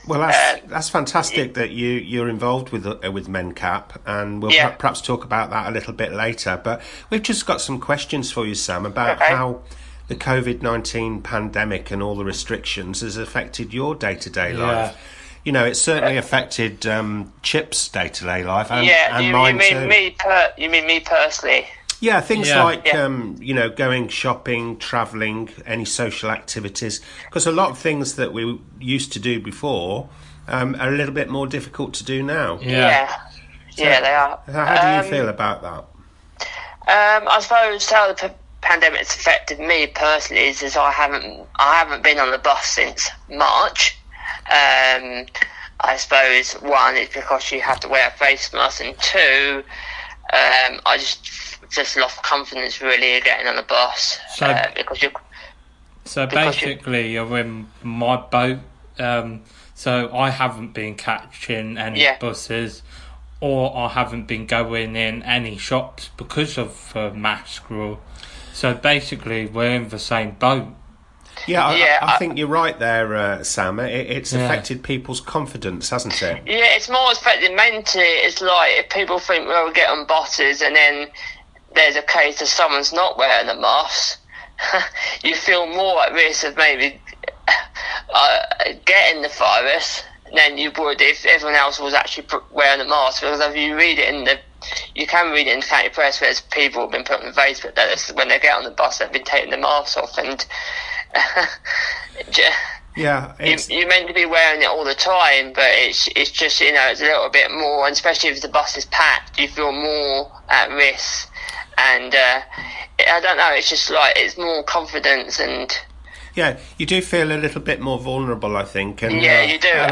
MenCap interview 2020.